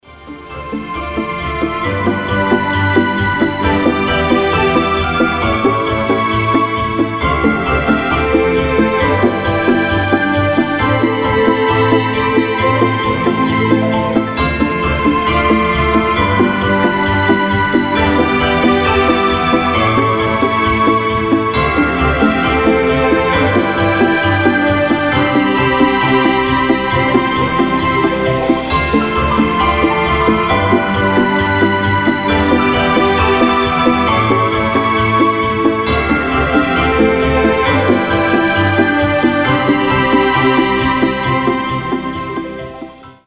• Качество: 320, Stereo
мелодичные
без слов
добрые
праздничные
волшебные
рождественские